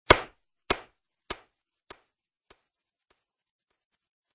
Descarga de Sonidos mp3 Gratis: golpe madera maciza.